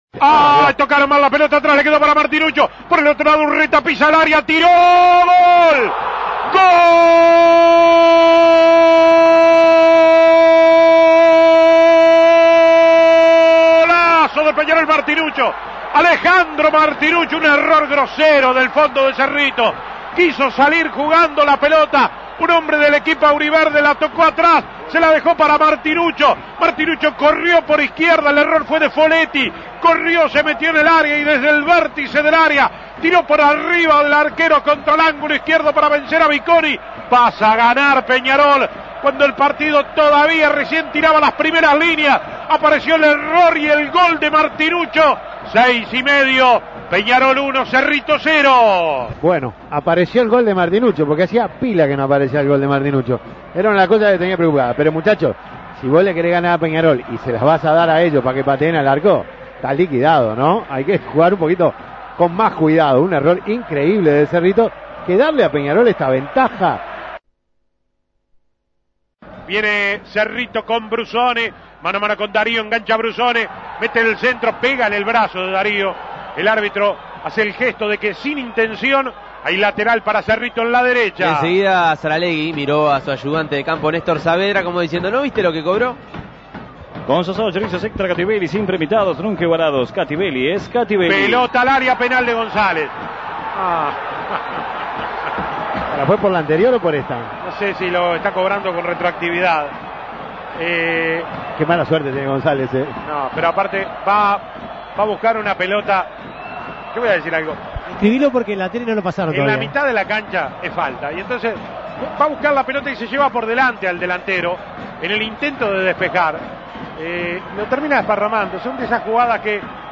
Goles y comentarios Escuche los goles de Peñarol-Cerrito Imprimir A- A A+ Peñarol le ganó en un partido electrizante a Cerrito 3-1 y amplió su ventaja en la tabla anual.